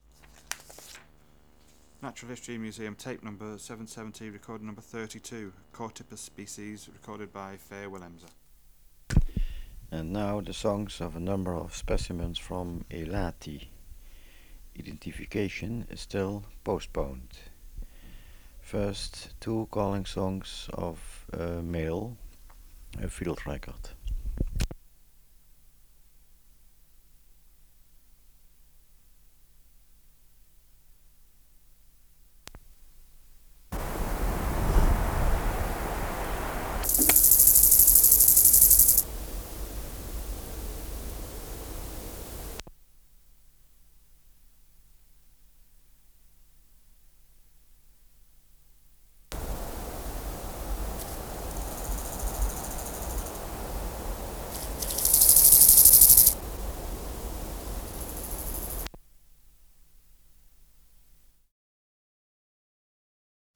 591:32 Chorthippus sp.